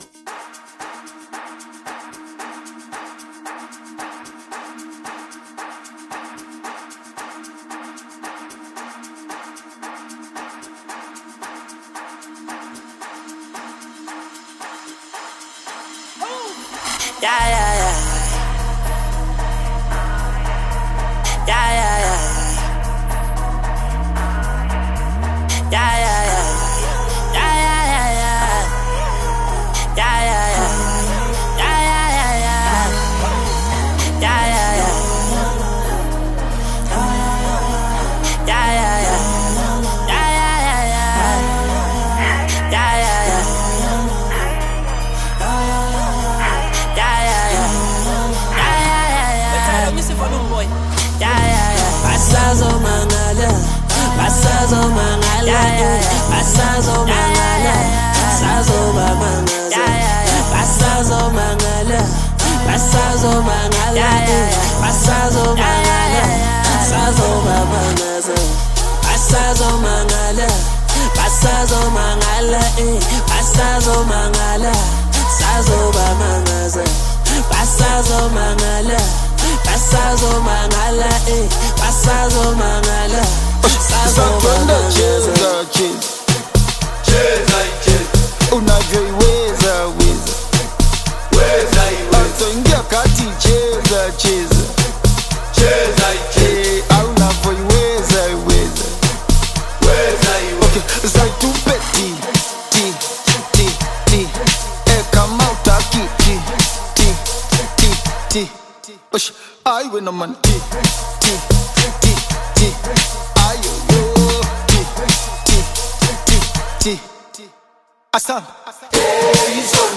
This vibrant record
Electronic